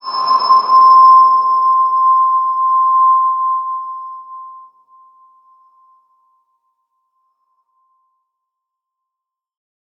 X_BasicBells-C4-pp.wav